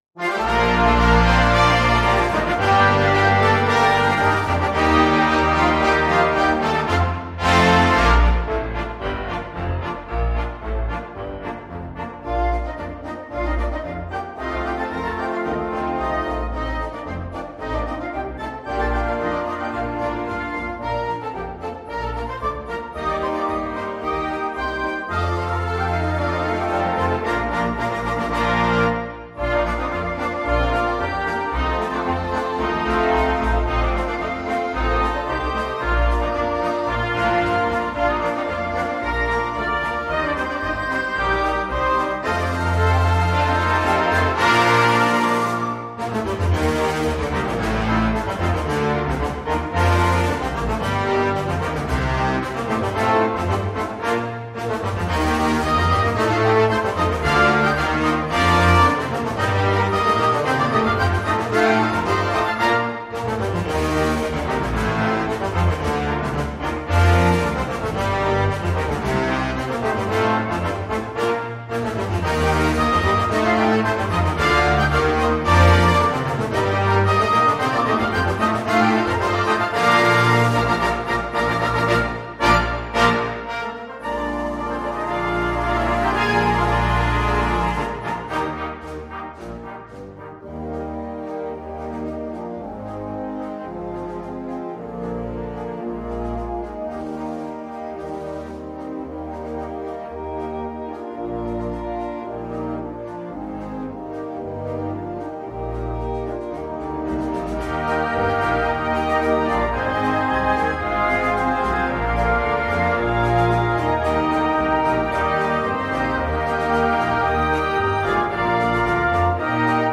Noten für Blasorchester